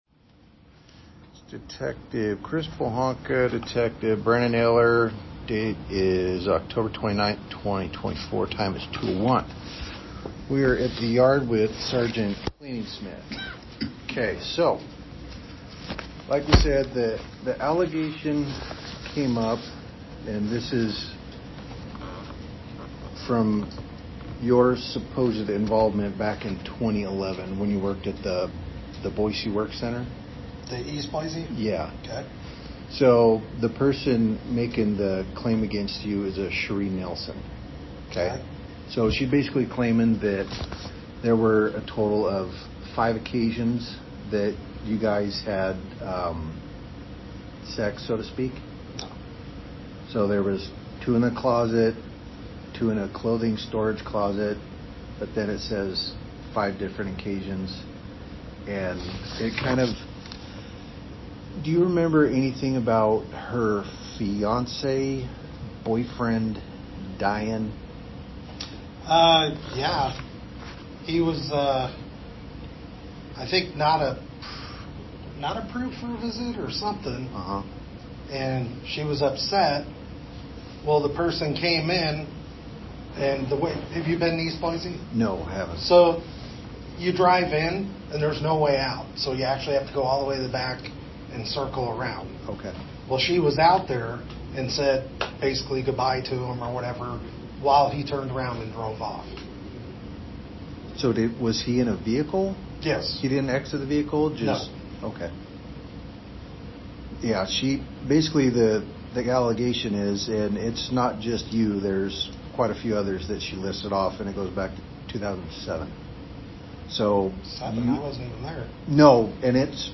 This 9-minute, unedited audio of the interview was obtained by InvestigateWest through a public records request.